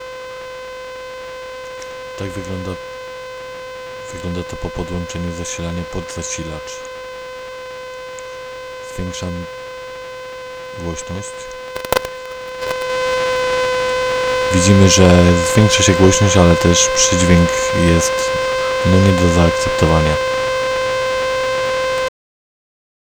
Słyszalny – niestety – jest przydźwięk, nieznacznie malejący przy przyłożeniu palca do kabla masy (nie fachowe ale co tam ).
Po podłączeniu zasilacza brum znacznie się zwiększa.
proba mikrofonu 2.wav